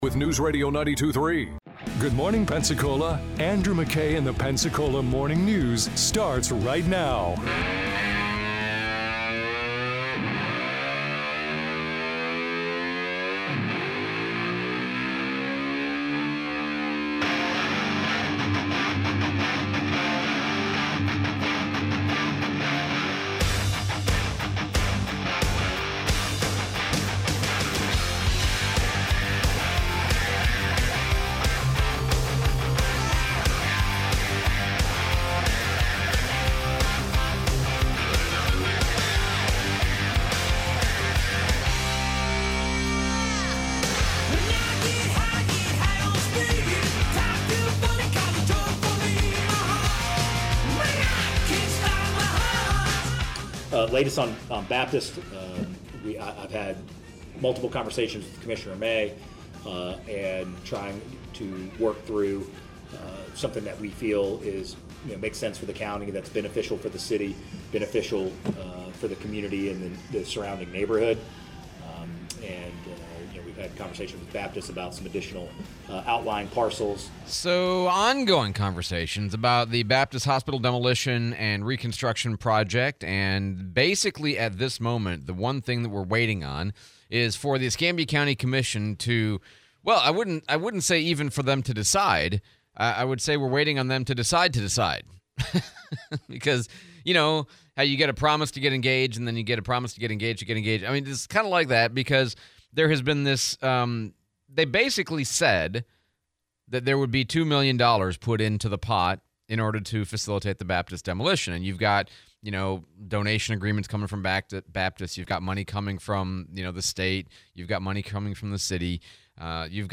Baptist hospital demolition, interview with Mayor DC Reeves